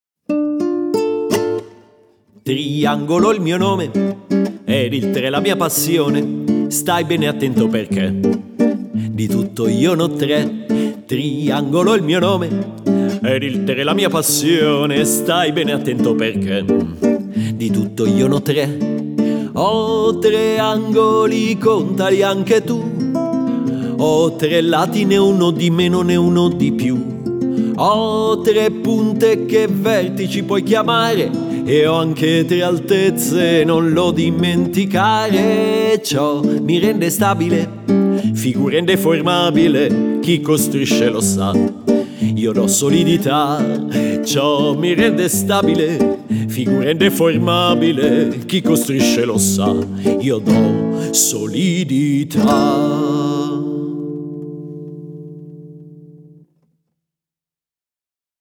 Musica, chitarra e voce